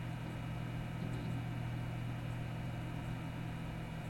ventilator_potolok.ogg